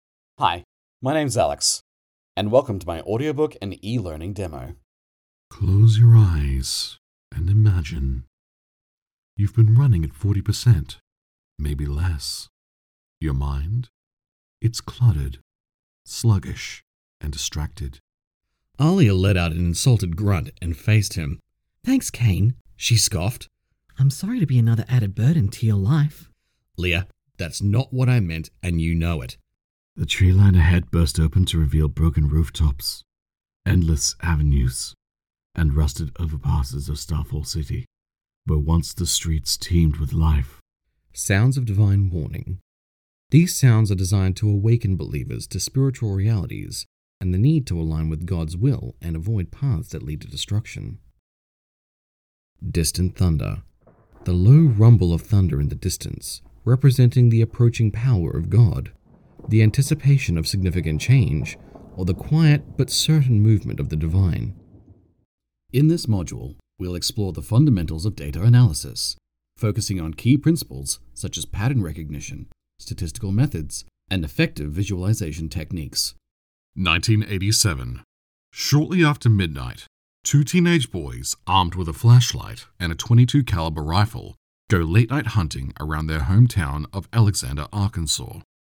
Audio Book Voice Over Narrators
Adult (30-50) | Older Sound (50+)
0717Audiobook___E_learning_Demo.mp3